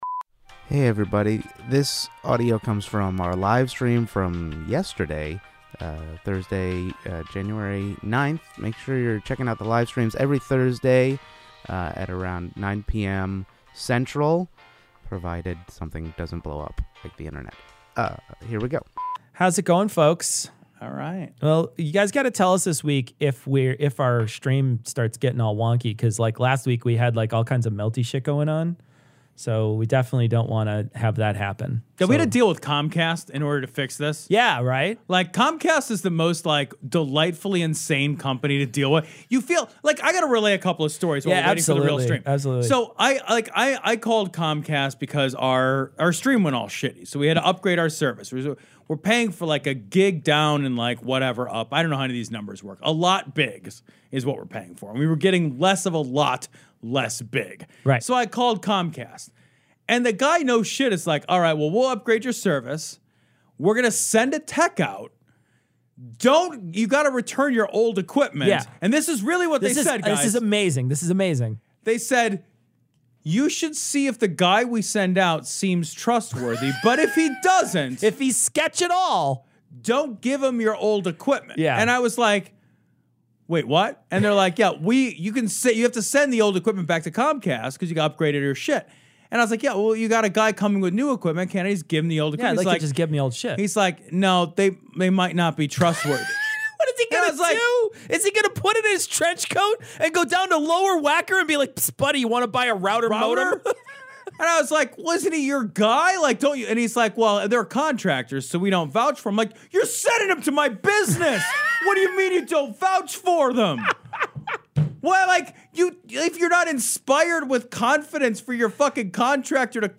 Audio from our Thursday night livestream 1/9/2020